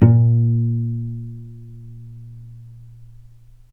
healing-soundscapes/Sound Banks/HSS_OP_Pack/Strings/cello/pizz/vc_pz-A#2-mf.AIF at 61d9fc336c23f962a4879a825ef13e8dd23a4d25
vc_pz-A#2-mf.AIF